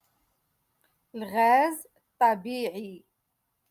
Moroccan Dialect - Rotation Two- Lesson Fifty One